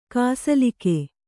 ♪ kāsalike